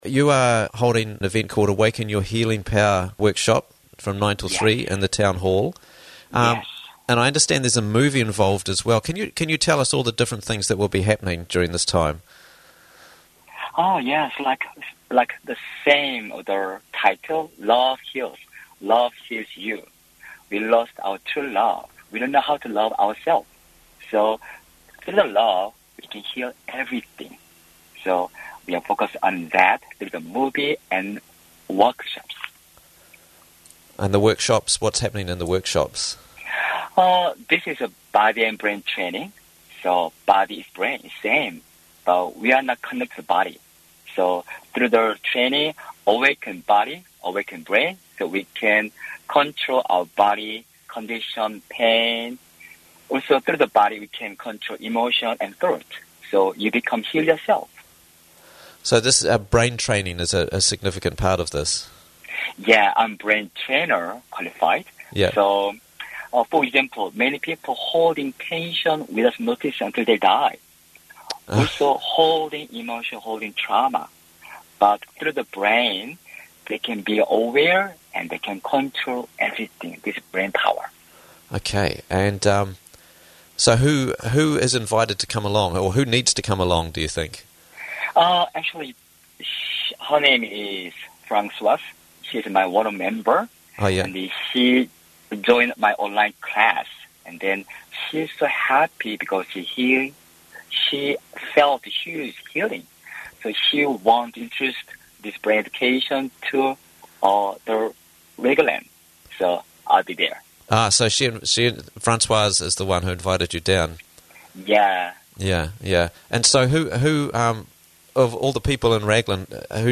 Healing Workshop This Weekend - Interviews from the Raglan Morning Show